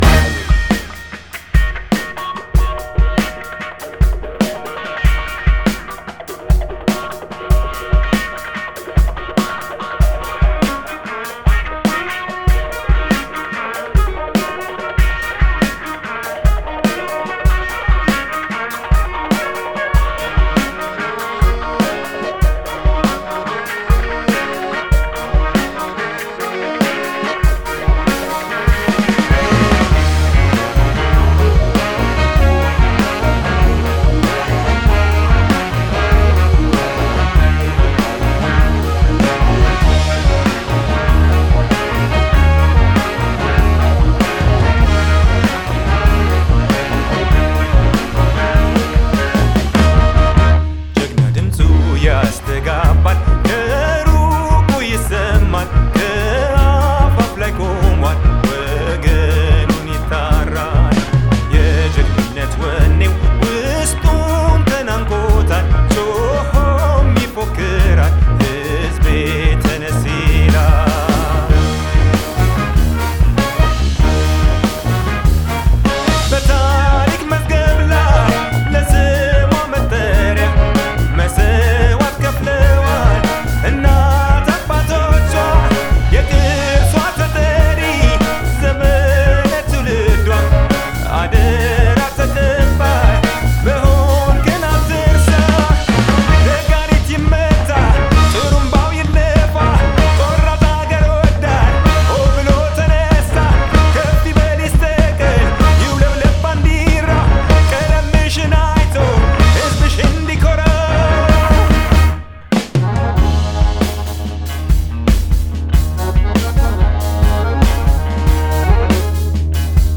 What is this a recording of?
Genre: Ethiopian.